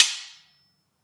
剑与冲突包 " 剑与冲突 (29)
描述：这个声音是用iPod touch录制的（第5代） 我正在为一个正在进行的项目需要一些剑音效果，在我做完之后，我想我会在freesound上免费赠送它们！ 你听到的声音实际上只是几个大厨房铲子碰撞在一起
标签： 金属的iPod 叮当 冲突 命中 铮铮 来袭 斜线 金属 金属对金属 IPOD 不锈钢 冲击 振铃 削去 发生冲突
声道立体声